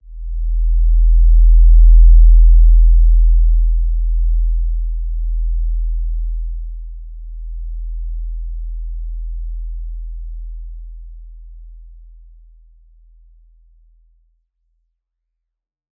Basic-Tone-E1-mf.wav